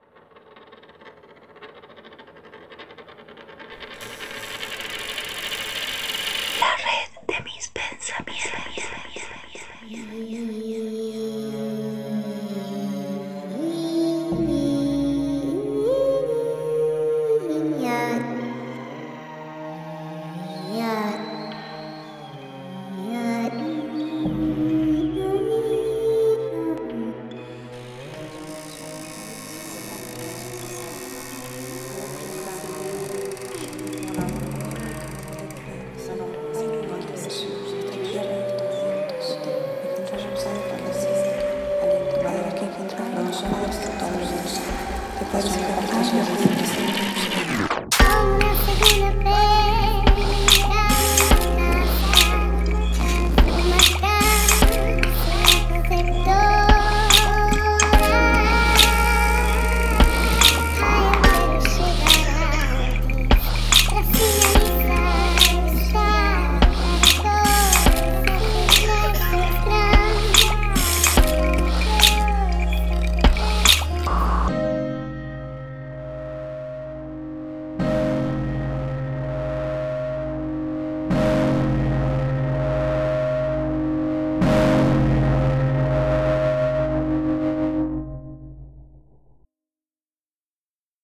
OBRAS RADIOFÓNICAS
Se rompe la máquina, se desalinea algo y se genera una obra que quiere asemejarse a algo estándar pero que suena caótica, disonante y que es incómoda de escuchar.